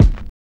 MAIN CRUNCH.wav